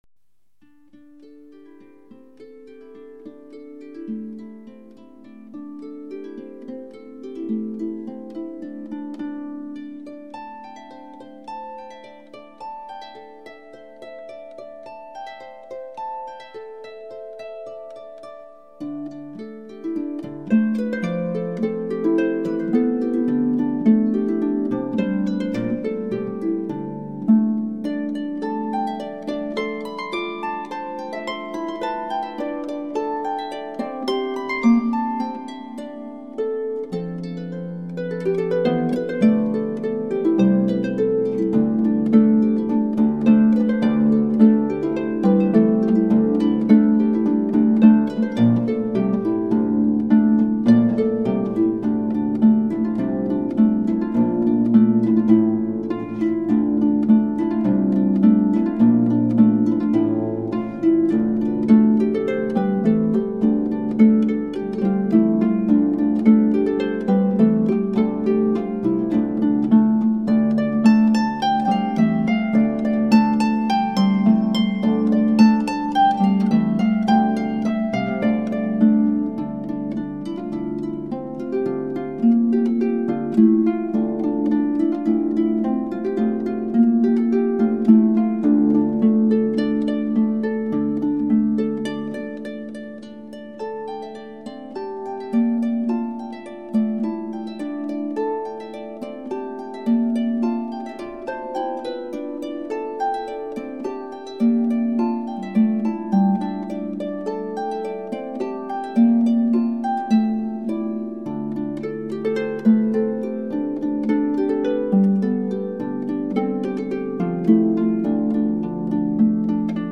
based on the traditional Irish/Scottish melody